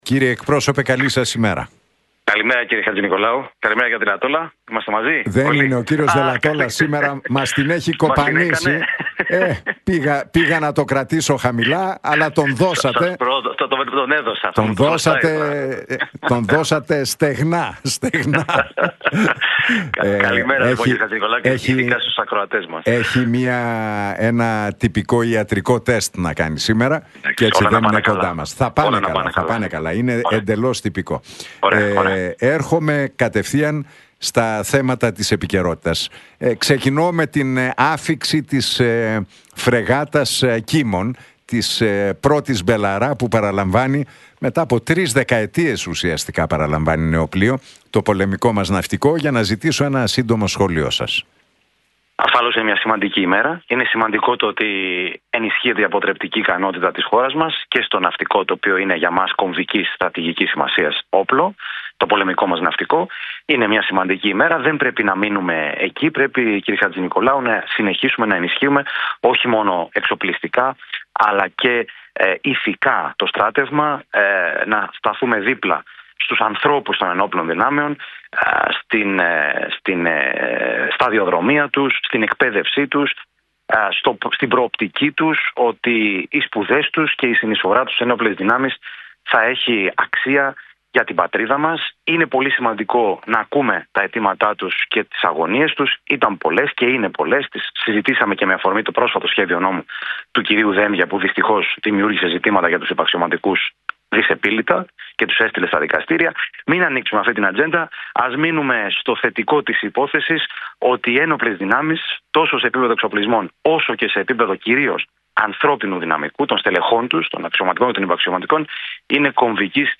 Για την άφιξη της φρεγάτας Belharra “Κίμων”, τις τουρκικές προκλήσεις, τα μπλόκα των αγροτών και τις δημοσκοπήσεις μίλησε, μεταξύ άλλων ο κοινοβουλευτικός εκπρόσωπος του ΠΑΣΟΚ-ΚΙΝΑΛ, Δημήτρης Μάντζος στην εκπομπή του Νίκου Χατζηνικολάου στον Realfm 97,8.